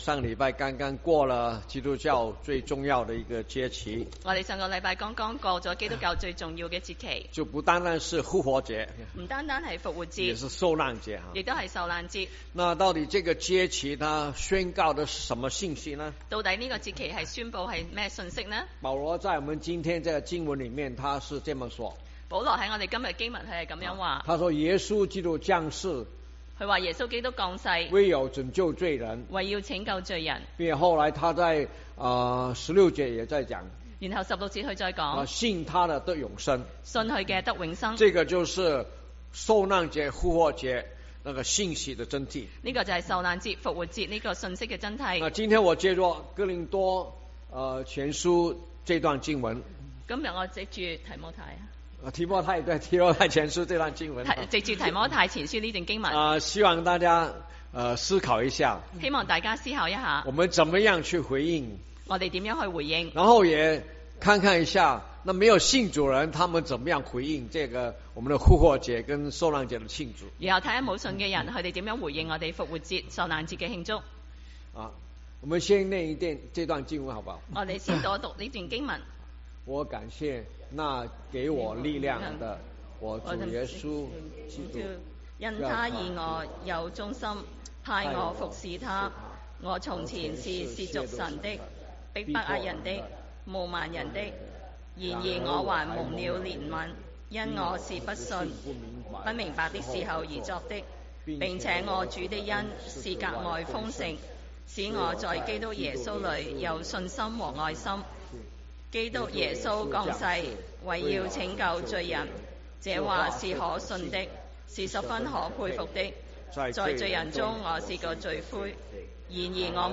中文堂講道信息 | First Baptist Church of Flushing